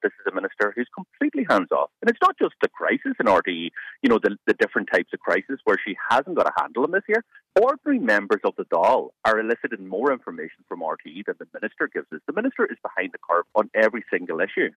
Donegal Deputy Pearse Doherty, says Minister Catherine Martin is not in control of her brief: